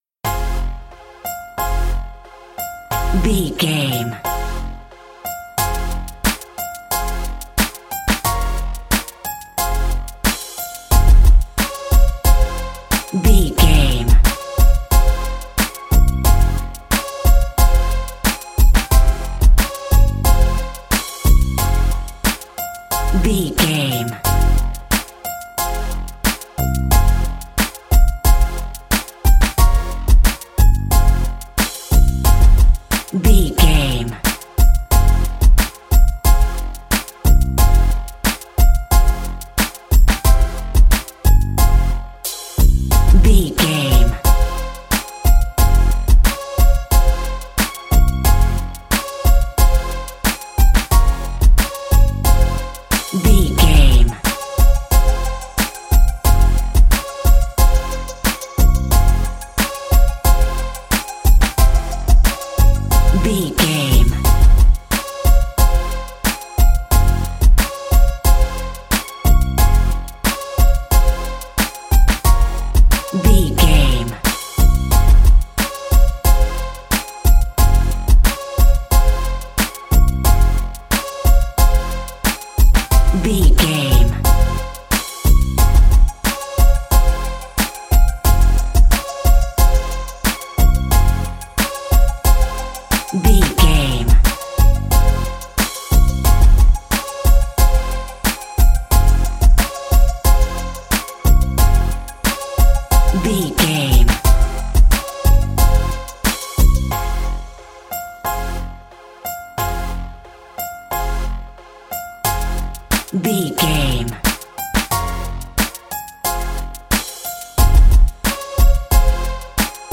Aeolian/Minor
drum machine
synthesiser
electric piano
hip hop
soul
Funk
acid jazz
confident
energetic
bouncy
funky
aggressive
hard hitting